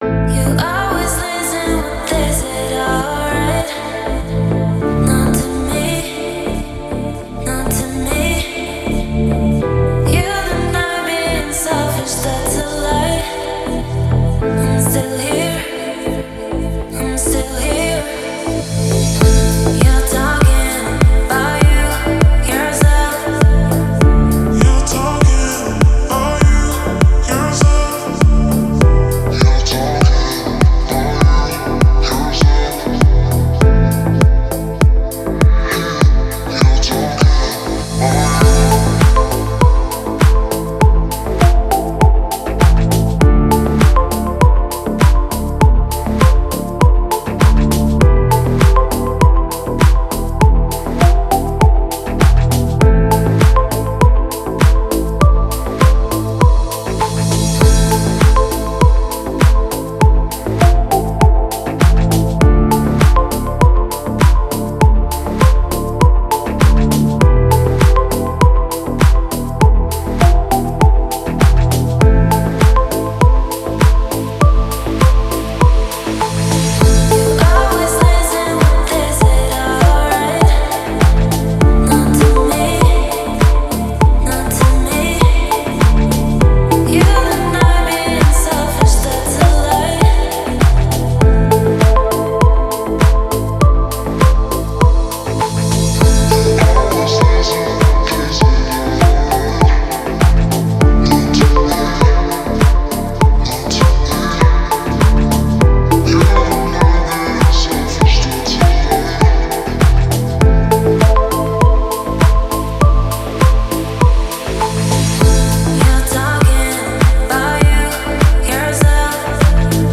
дип хаус